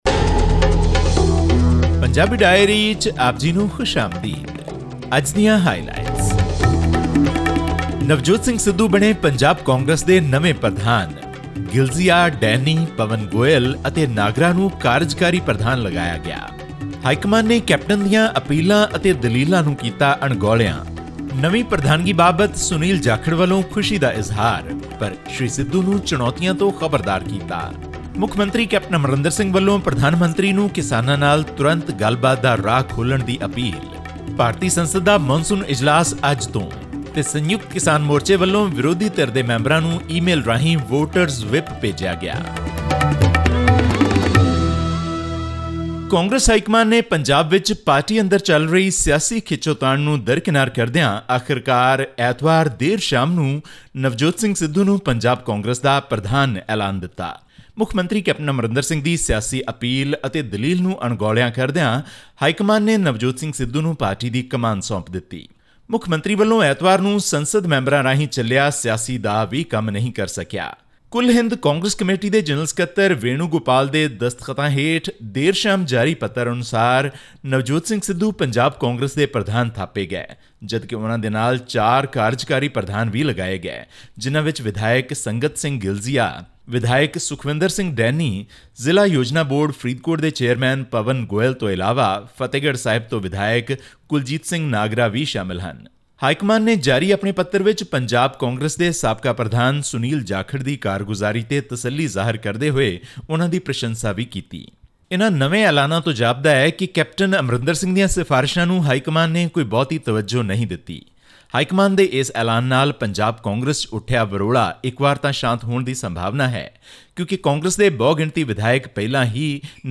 After rounds of talks with the national high command of the Congress party in India, Navjot Singh Sidhu has been appointed president of Punjab Pardesh Congress. This and much more, in our weekly news bulletin from Punjab, India.